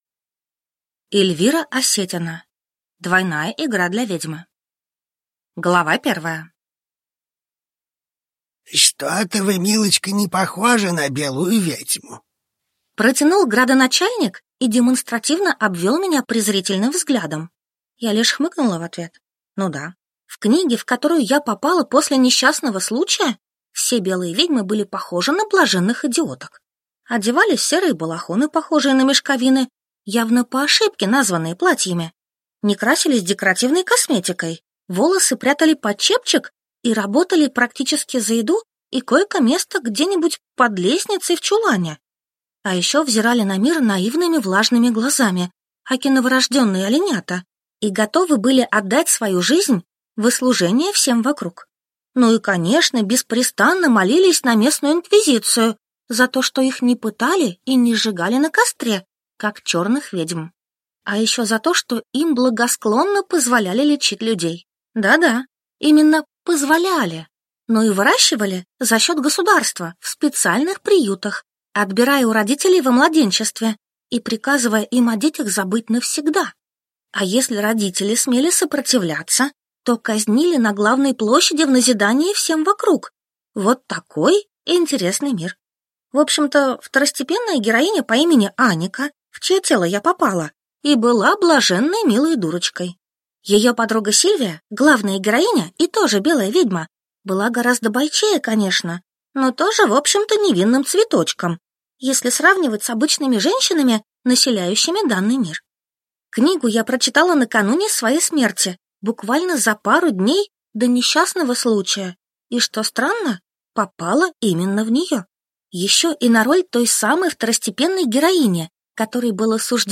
Аудиокнига Двойная игра для ведьмы | Библиотека аудиокниг